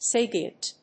音節sa・pi・ent 発音記号・読み方
/séɪpiənt(米国英語)/